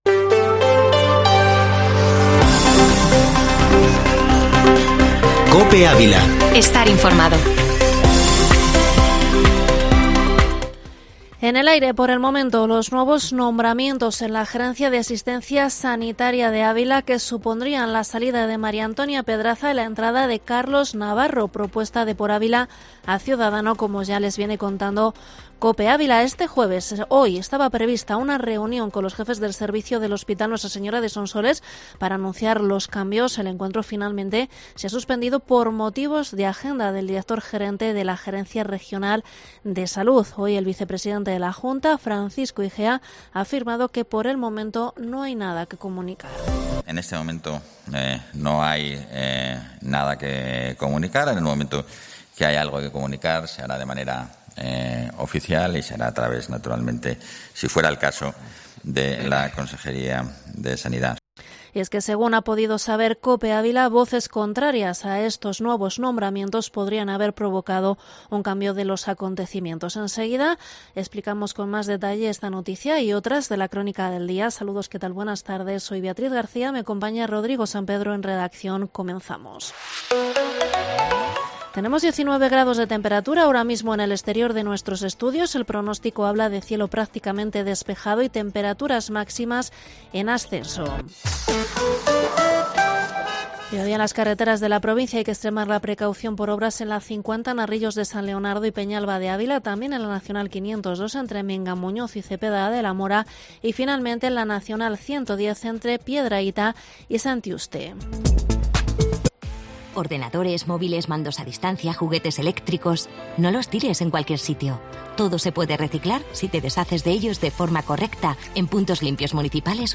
informativo Mediodía COPE ÁVILA 14/10/2021